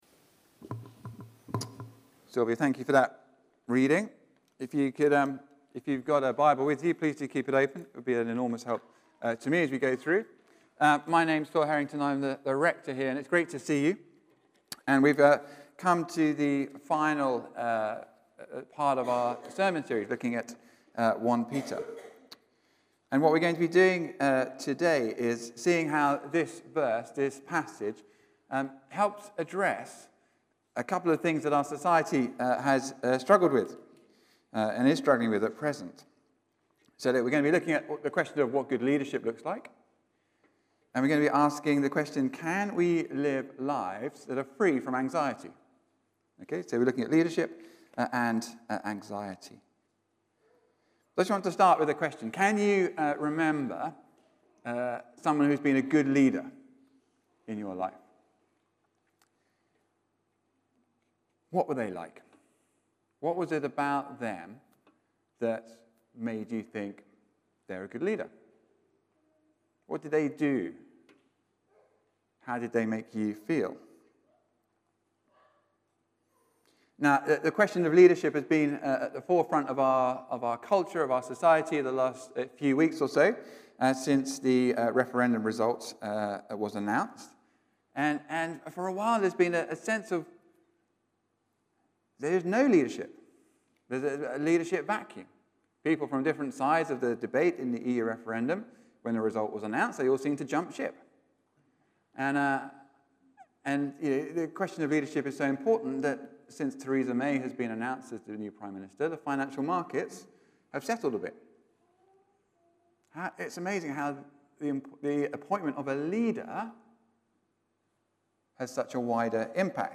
Listen to Sermons : St Marys